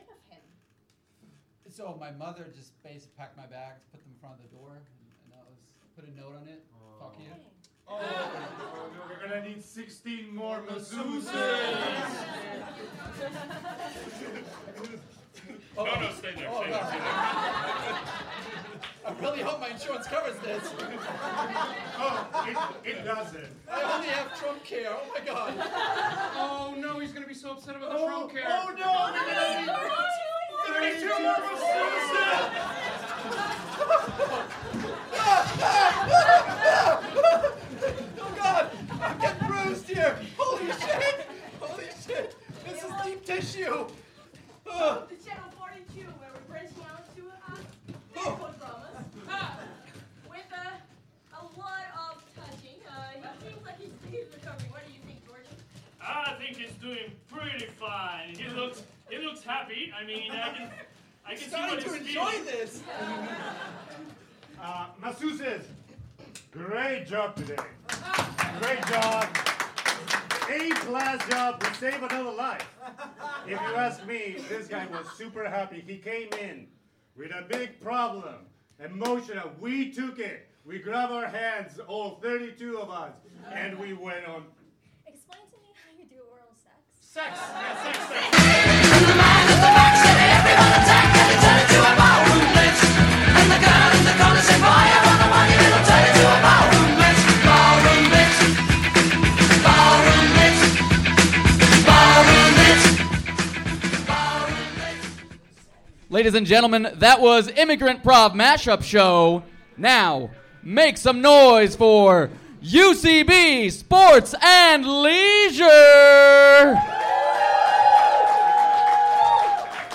A special live show from the 19th annual Del Close Marathon in New York. UCB Sports & Leisure's Matt Walsh sits down with renown "human juke box" duo Little Coin (Horatio Sanz) & Big Money (Tim Meadows) to discuss their early days of fame as well as hear them perform some of their biggest hits.